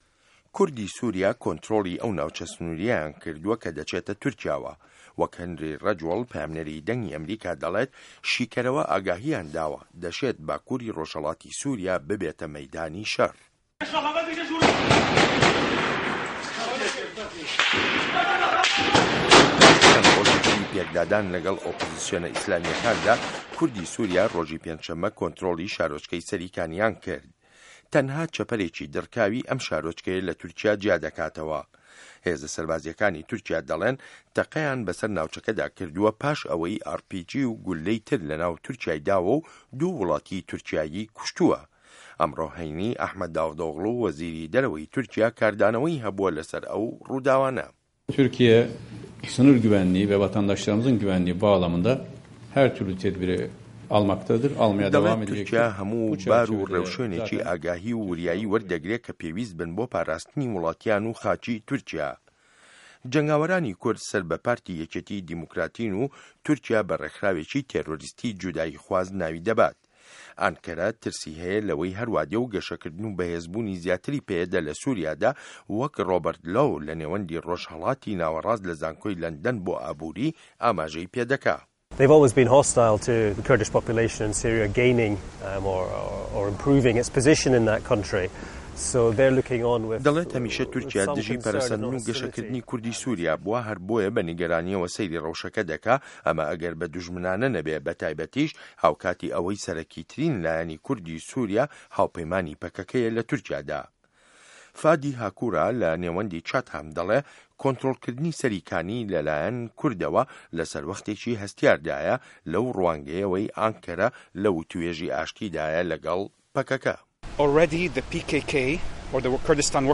ڕاپـۆرتێـک سه‌باره‌ت به‌ نیگه‌رانیـیه‌کانی تورکیا له‌ کوردی سوریا